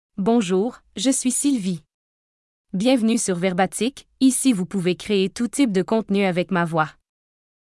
FemaleFrench (Canada)
Voice sample
Female
Sylvie delivers clear pronunciation with authentic Canada French intonation, making your content sound professionally produced.